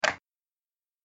دانلود آهنگ کلیک 46 از افکت صوتی اشیاء
جلوه های صوتی
دانلود صدای کلیک 46 از ساعد نیوز با لینک مستقیم و کیفیت بالا